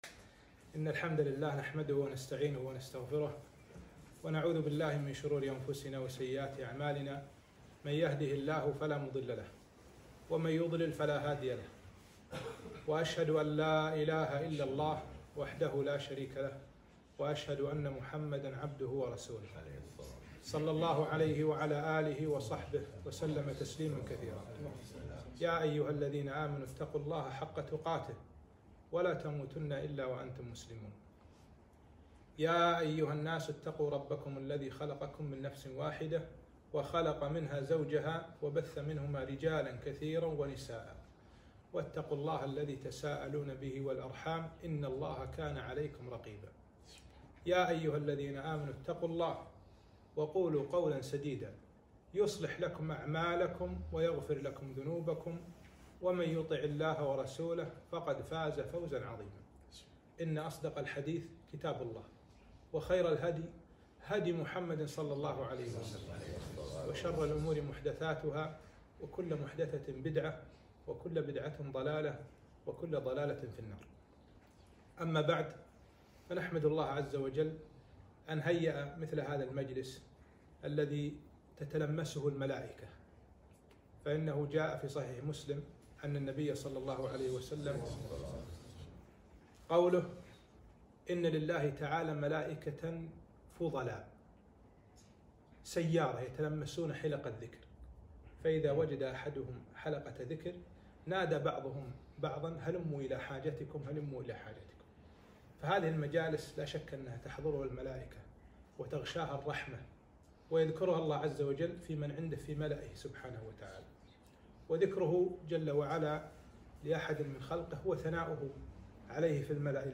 محاضرة - فَفِرُّوا إِلَى اللَّهِ